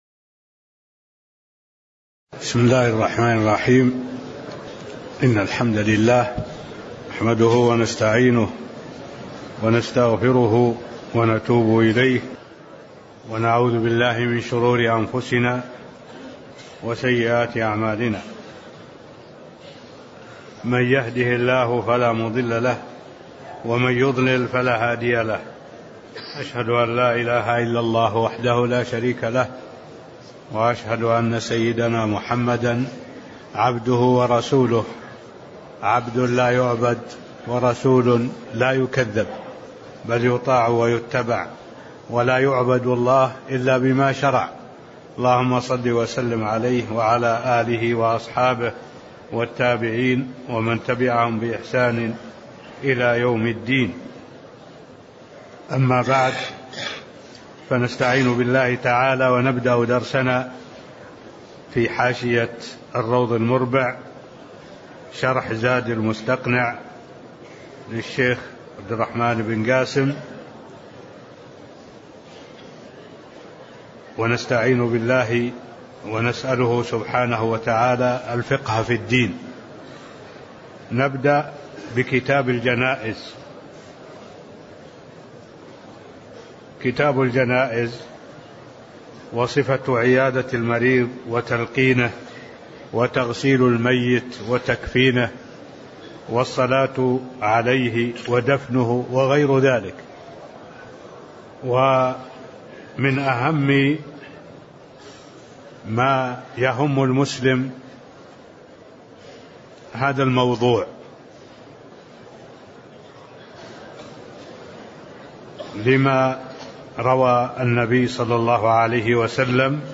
تاريخ النشر ٢٦ ذو الحجة ١٤٢٨ هـ المكان: المسجد النبوي الشيخ: معالي الشيخ الدكتور صالح بن عبد الله العبود معالي الشيخ الدكتور صالح بن عبد الله العبود صفة عيادة المريض وتلقينه (001)